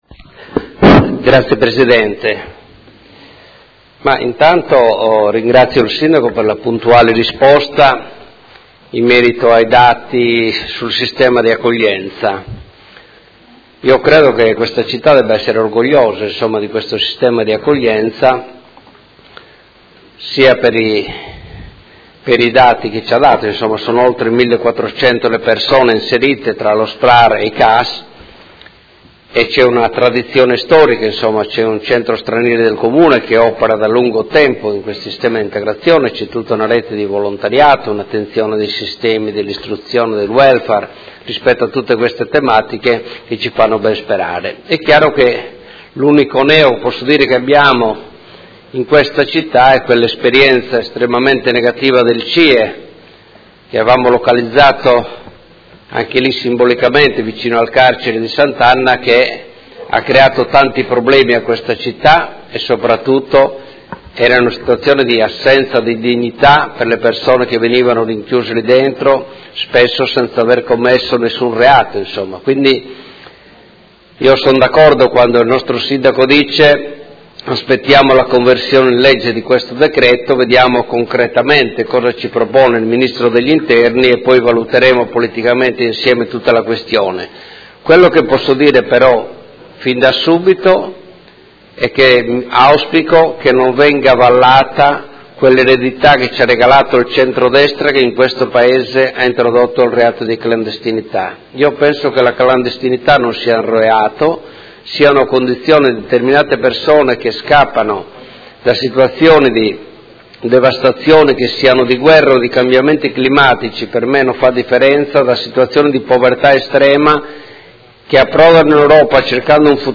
Marco Cugusi — Sito Audio Consiglio Comunale
Seduta del 16/03/2017. Dibattito su interrogazione del Consigliere Campana (Gruppo Per Me Modena), del Consigliere Cugusi (SEL) e del Consigliere Rocco (FaS-SI) avente per oggetto: Rifugiati e profughi a Modena: condizioni di accoglienza e prospettive